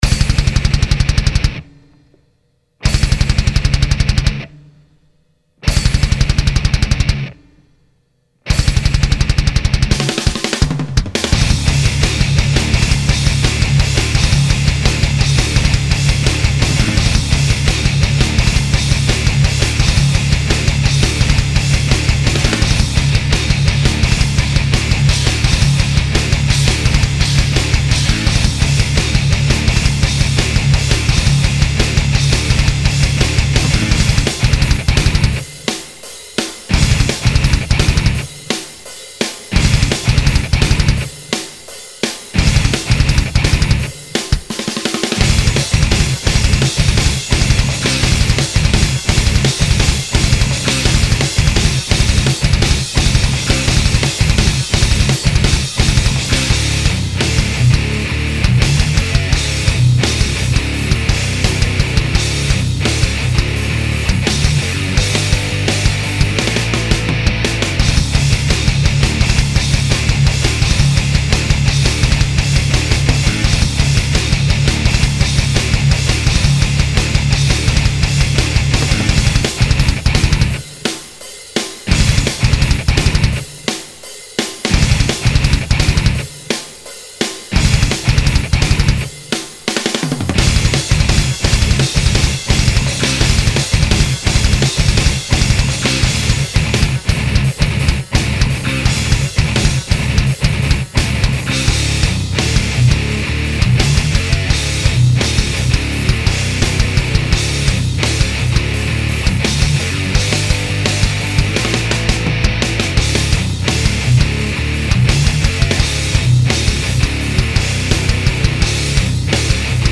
Vos Compos Metal
C'est un truc fait comme ça, surtout pour des tests de préprod d'un projet à moi, bref, voila le machin :
Ca démastique bien ! Je trouve le son global un peu sourd. Le riff vers 2'05 est excellent !
Il manque du chant pour que le morceau prenne toute sa dimension.
Plus sage rythmiquement que les précédents morceaux, ça reste en tête facilement.